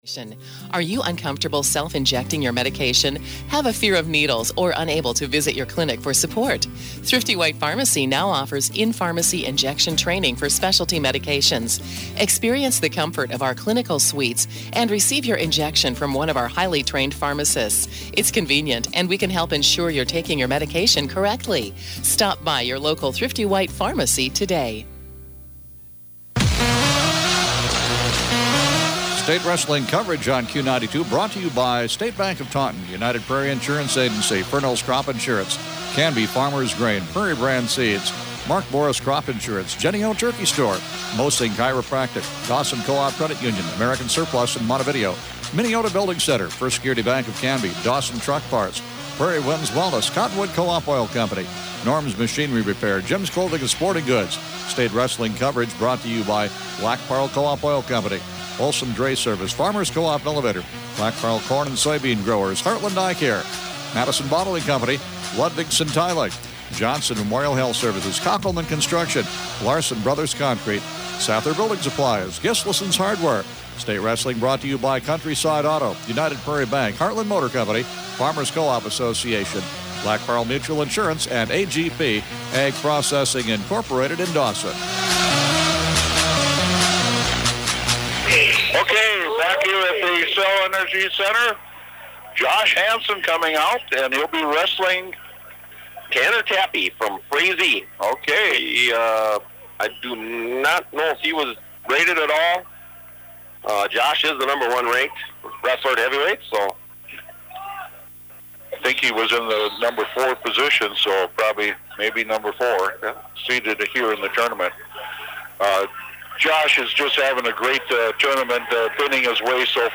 STATE WRESTLING MATCHES FROM THE EXCEL ENERGY CENTER